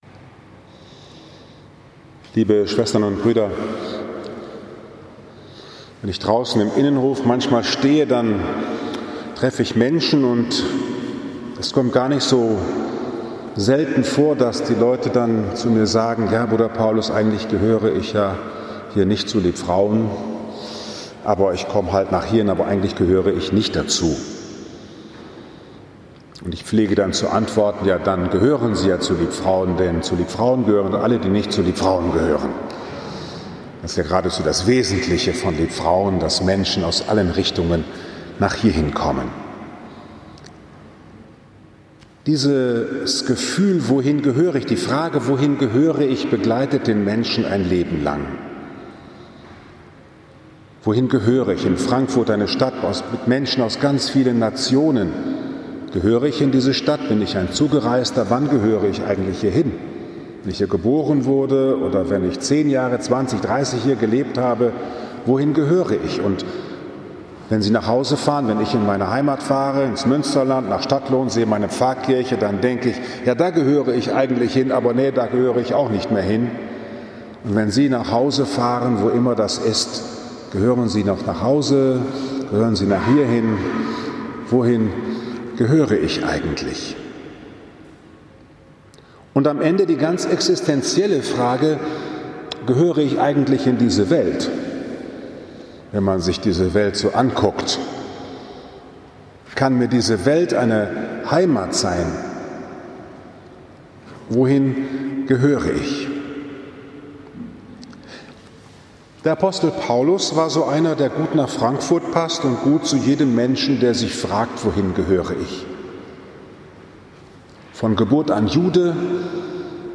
14. Juni 2020, 9.30 Uhr, Liebfrauenkirche Frankfurt am Main, 13. Sonntag im Jahreskreis A